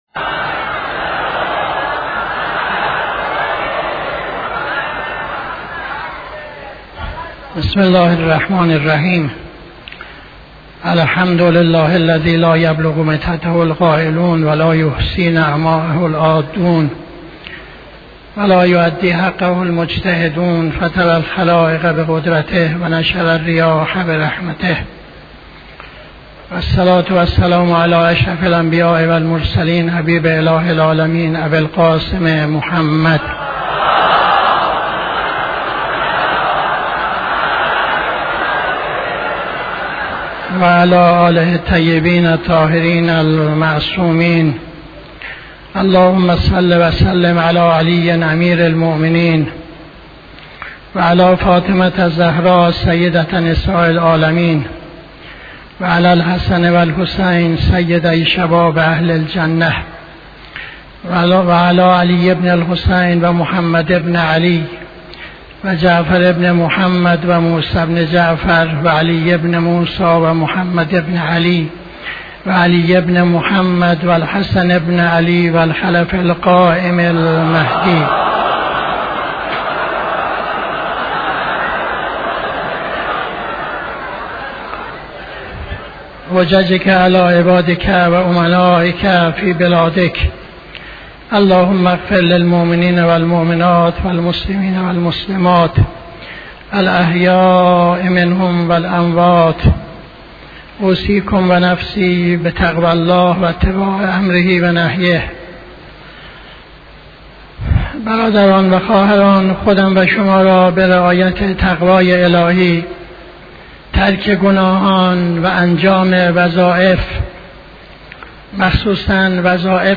خطبه دوم نماز جمعه 29-02-80